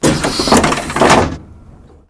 boost.wav